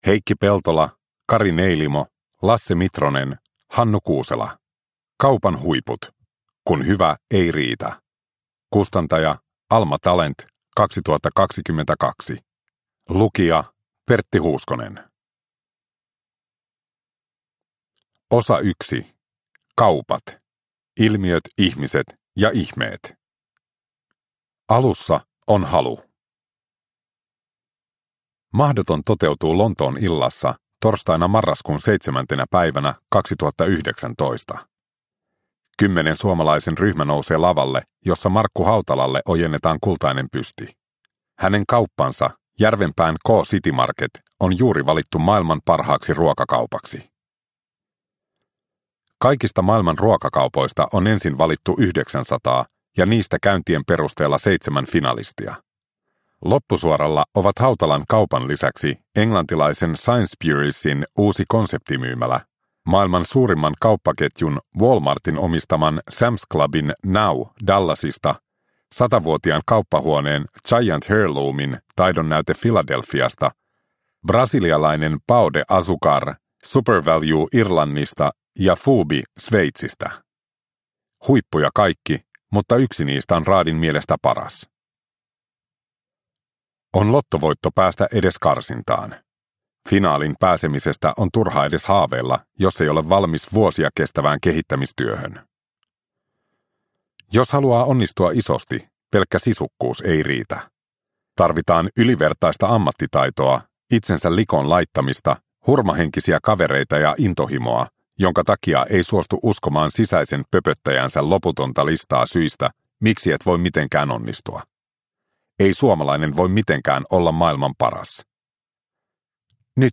Kaupan huiput – Ljudbok – Laddas ner